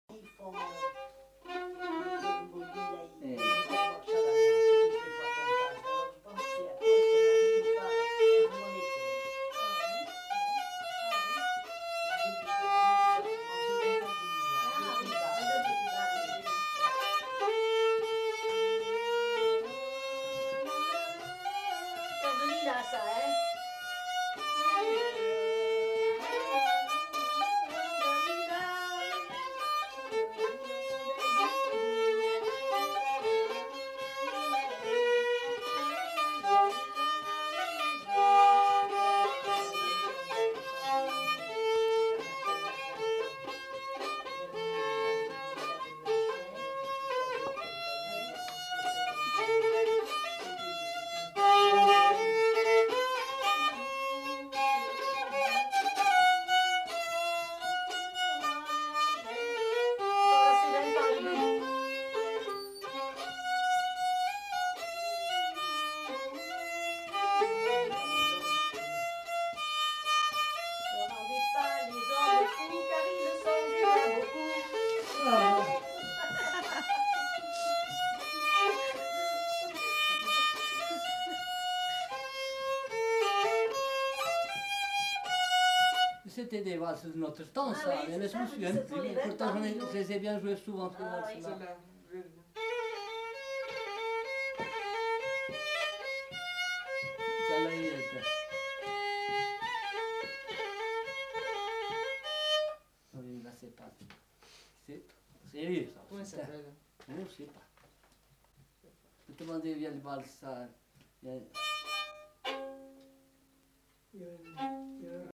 Aire culturelle : Limousin
Genre : morceau instrumental
Instrument de musique : violon
Danse : valse
Notes consultables : Bribes d'une autre valse.